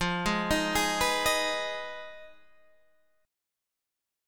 Fm7b5 chord